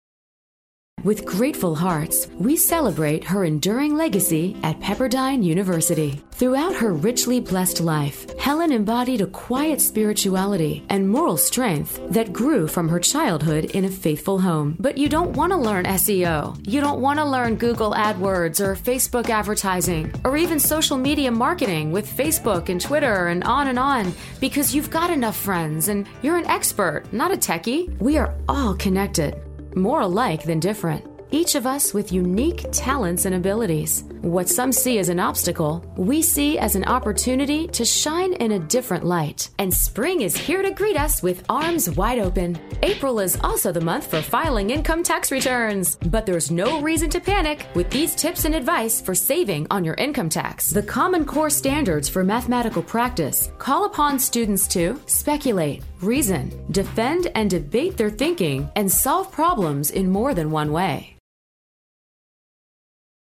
Female Narration Voice Talent and Voice Over - Speedy Spots
Narrations
A narrative style that’s great for lifestyle and technology pieces, as well as other projects needing a self-assured voice.